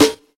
Boomy Snare One Shot A Key 21.wav
Royality free snare drum sound tuned to the A note. Loudest frequency: 1740Hz
boomy-snare-one-shot-a-key-21-iWG.mp3